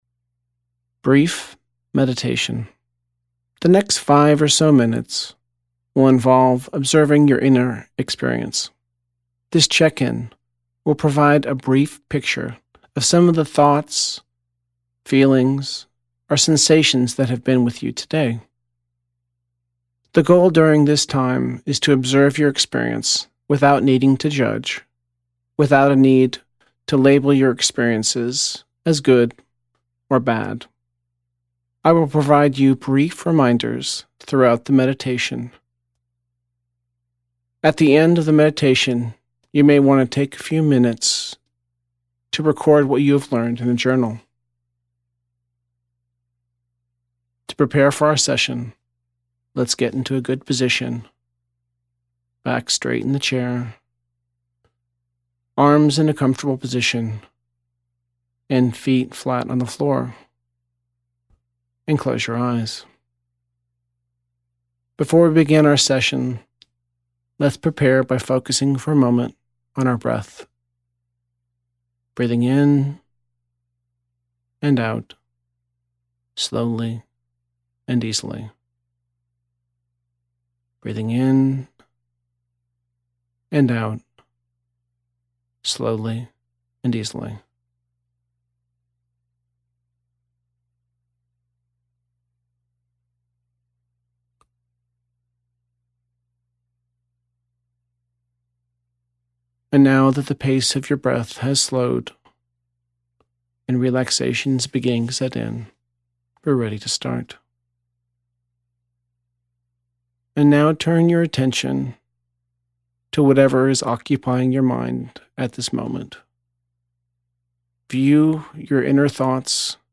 Brief Meditation